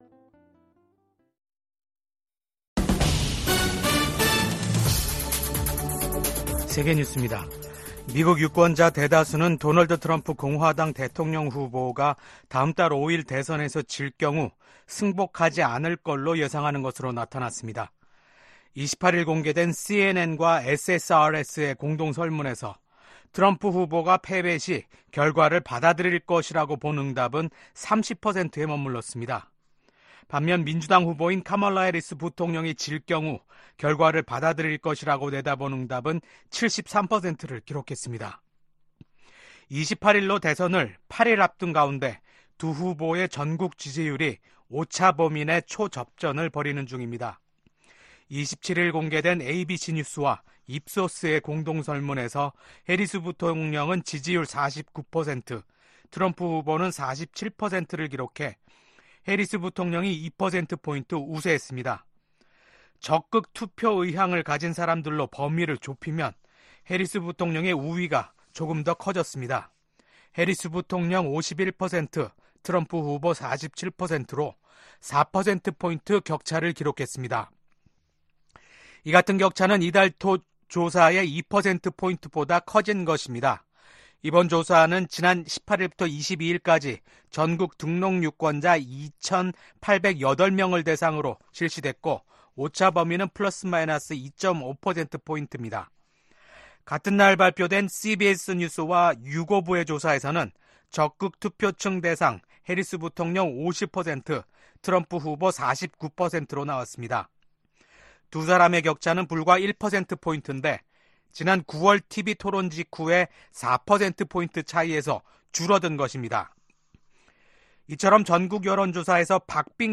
VOA 한국어 아침 뉴스 프로그램 '워싱턴 뉴스 광장' 2024년 10월 29일 방송입니다. 러시아에 파견된 북한군의 역할에 관심이 집중되고 있는 가운데 미한외교 안보 수장이 워싱턴에서 ‘2+2회담’을 갖고 해당 현안을 논의합니다. 미국과 한국, 일본의 안보 수장들이 워싱턴에서 만나 북한군의 러시아 파병에 깊은 우려를 나타냈습니다.